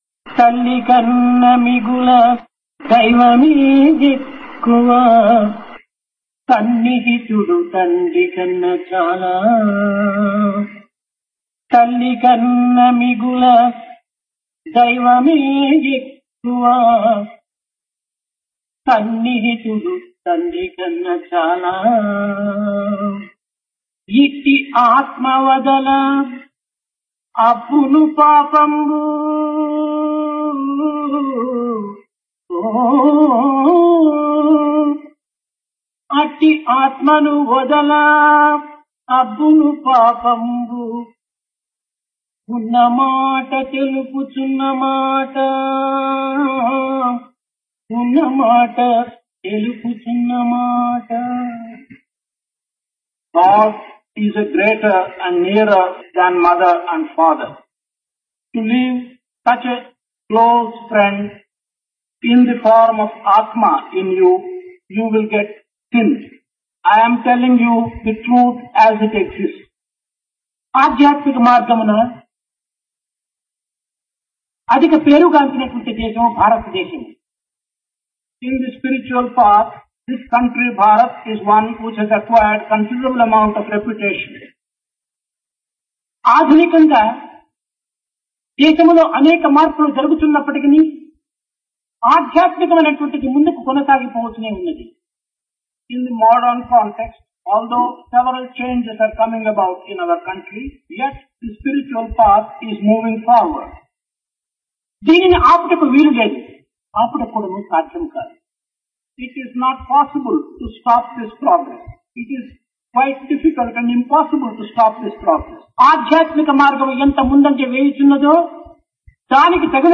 Divine Discourse of Bhagawan Sri Sathya Sai Baba, Summer Showers 1978
Place Brindavan Occasion Summer Course 1978